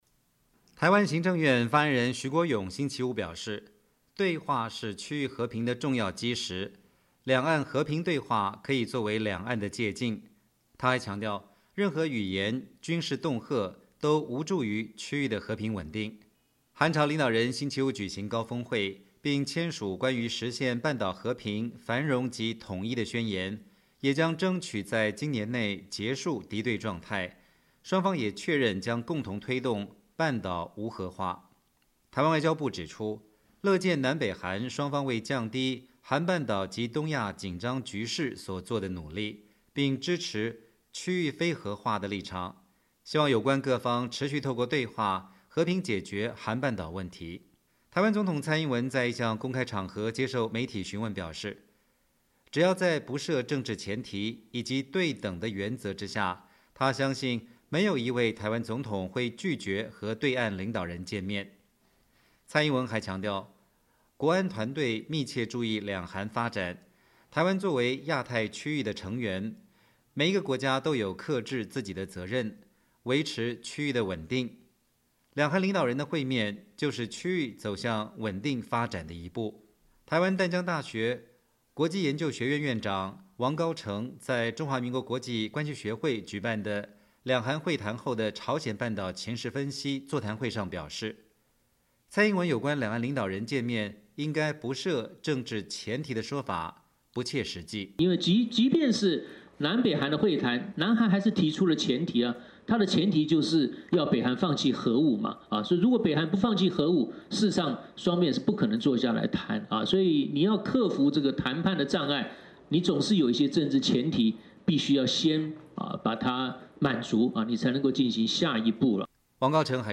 中华民国国际关系学会举办两韩会谈座谈会